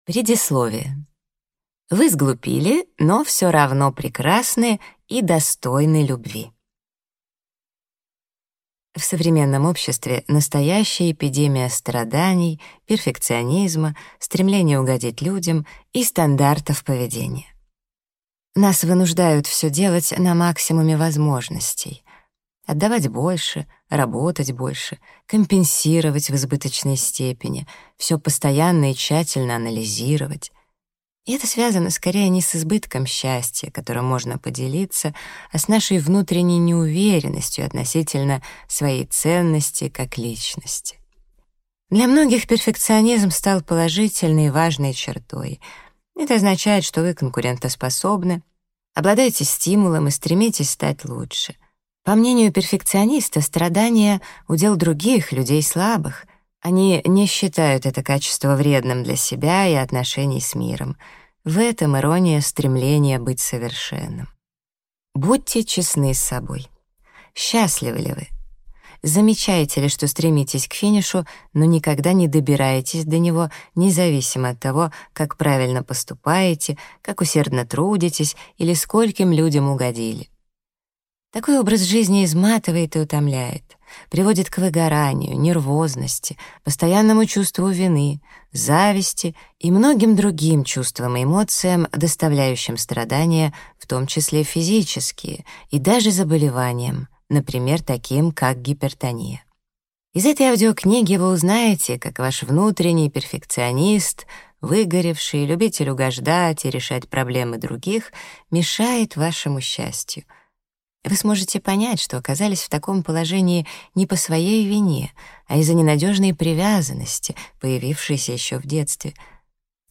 Аудиокнига Освобождение чувств. Как преодолеть последствия негативного детского опыта и не дать ему разрушить вашу жизнь | Библиотека аудиокниг